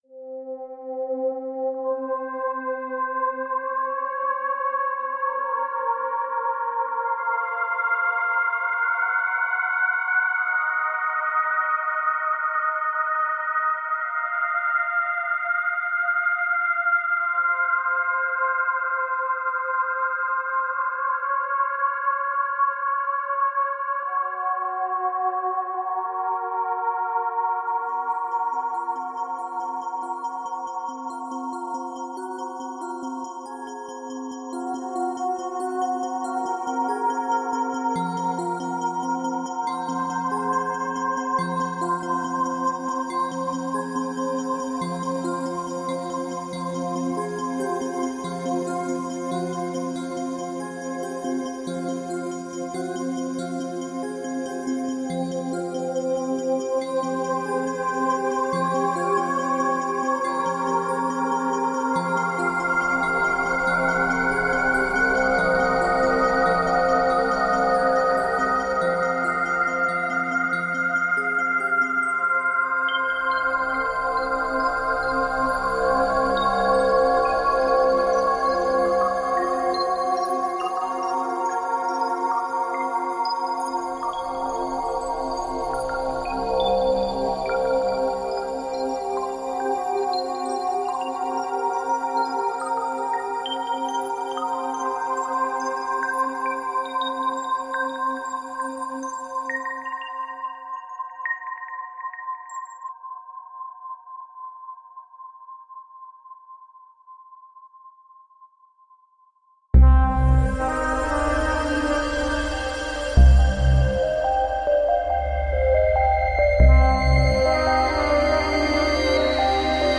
Tagged as: Ambient, Electronica